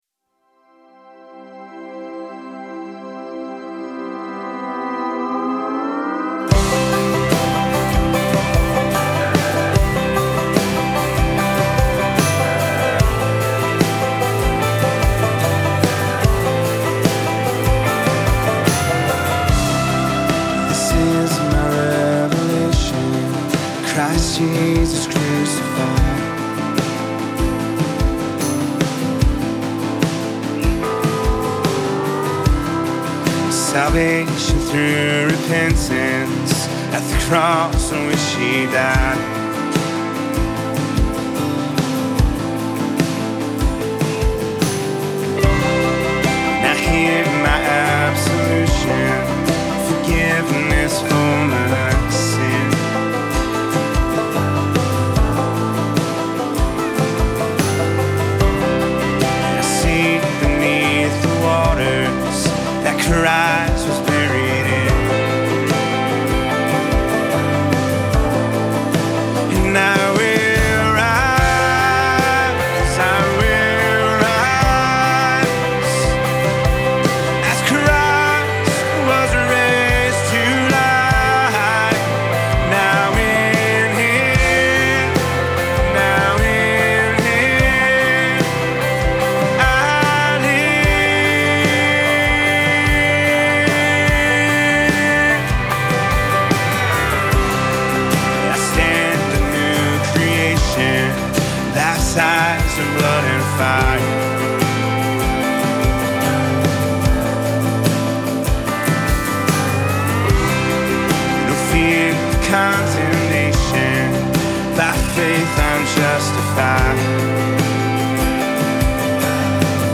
Full arrangement demo
5. Reference mp3’s with lead vocals
• Keys: C, Bb, and A
• Tempo: 74 bpm, 4/4 time
STYLE: RE-ARRANGEMENT
• Acoustic Guitar
• Banjo
• Electric Guitar (2 tracks)
• Electric Piano
• Pedal Steel
• Synth Bass